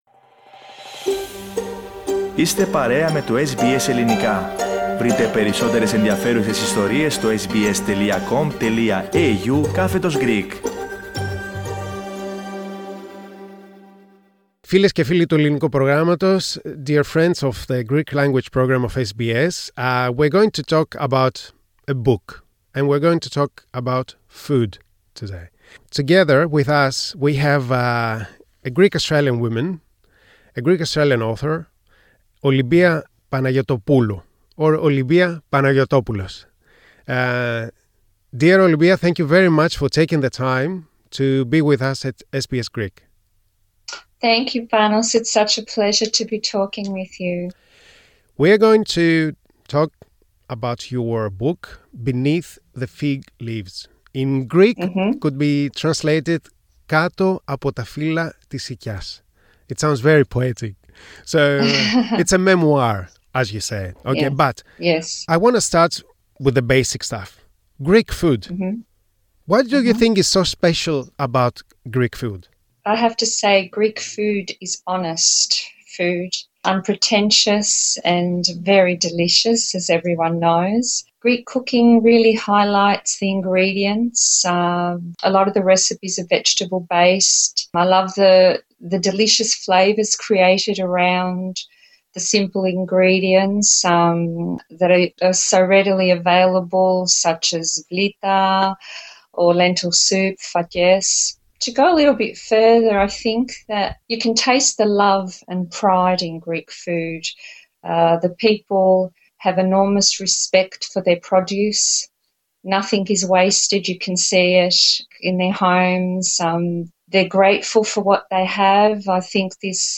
interview in English